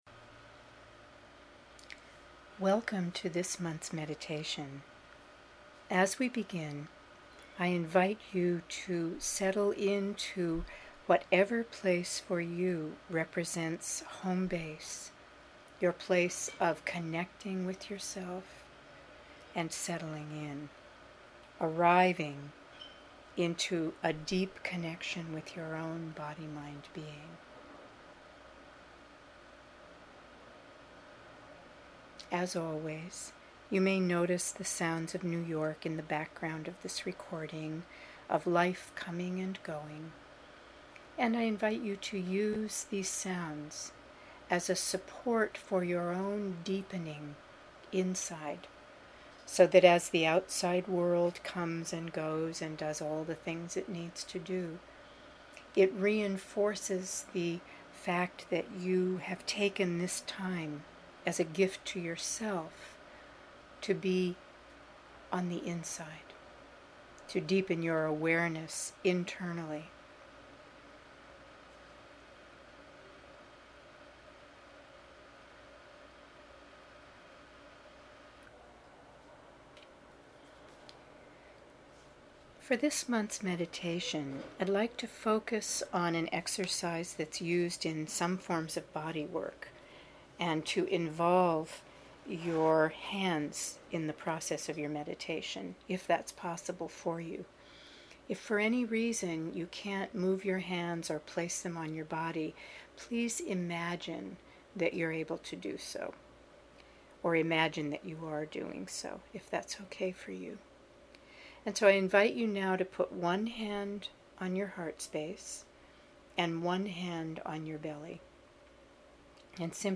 Here’s the audio version of this month’s meditation: